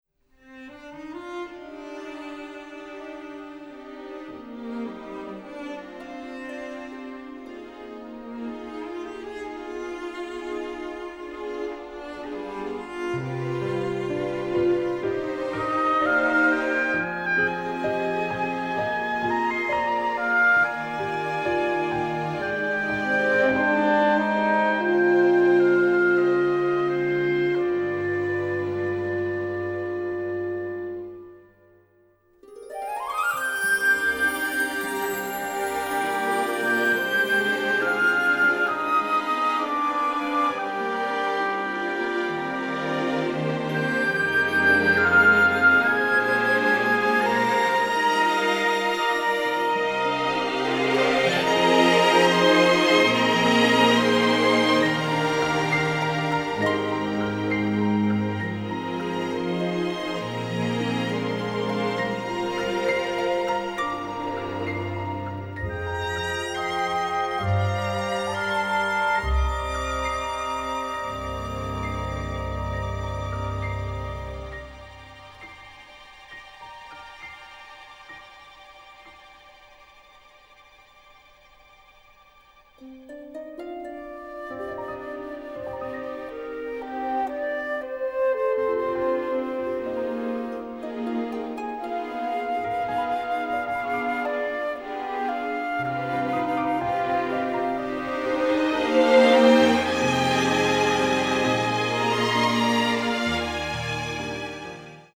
This orchestral album
A nice easy listening version of music